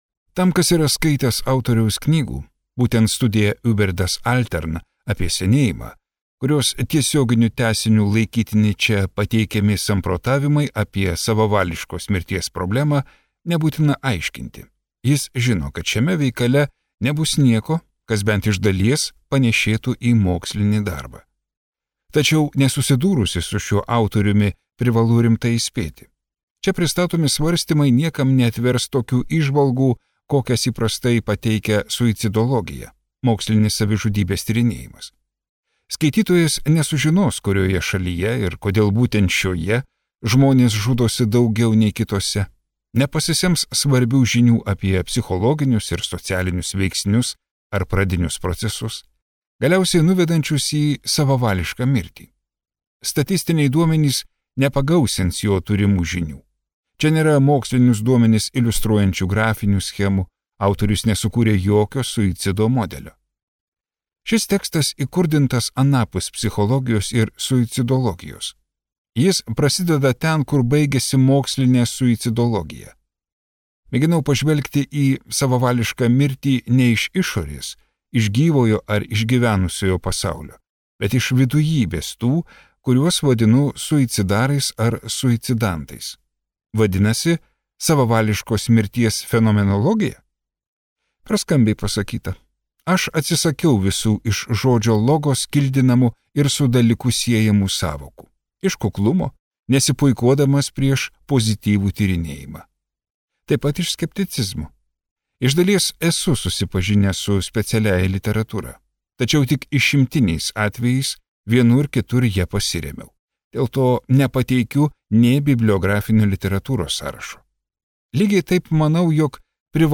Vokiečių rašytojo Jean Amery audioknyga „Apie savavališką mirtį“. Joje autorius narplioja skaudžią savižudybės temą.